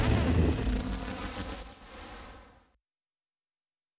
Engine 5 Stop.wav